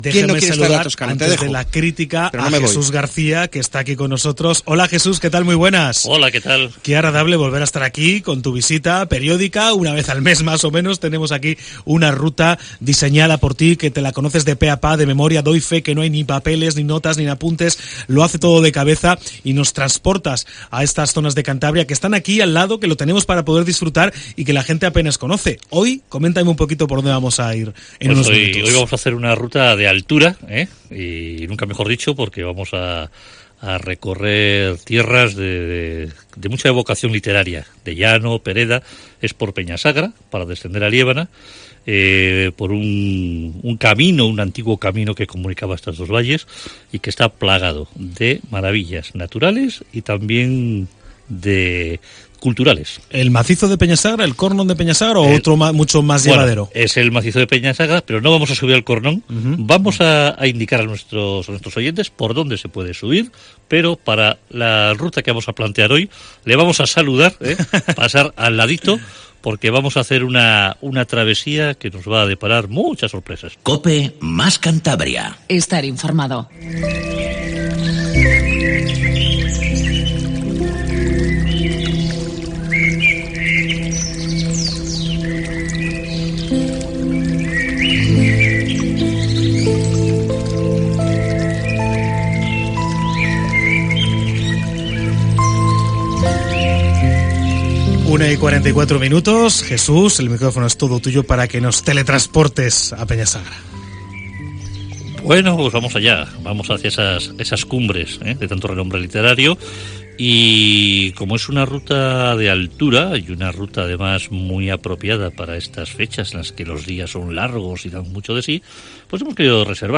en los estudios de Cope Cantabria